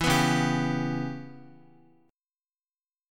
C#m7b5 chord